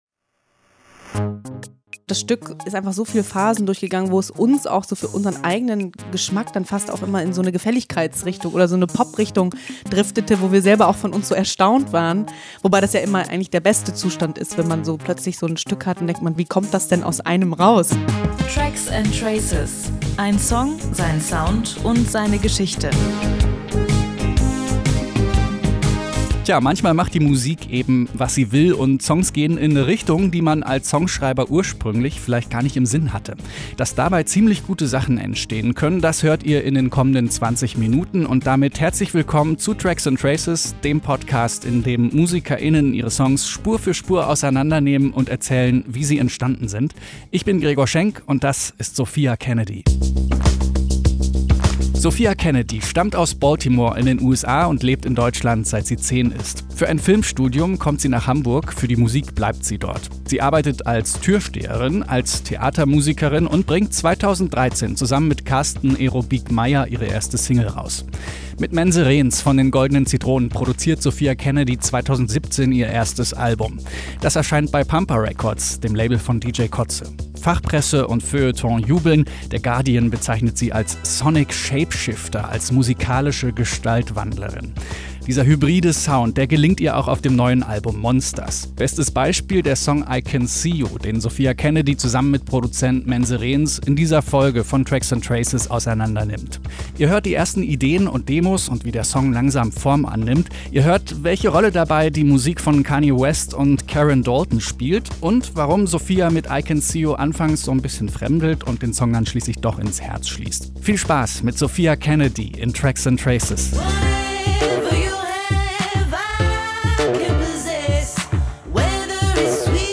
Von der Euphorie, die aus vermeintlichen Niederlagen entstehen kann. Ein Discopop-Hybrid mit bretternden Orgeln à la Kanye West und einer Stimme, die der Folk-Legende Karen Dalton Tribut zollt.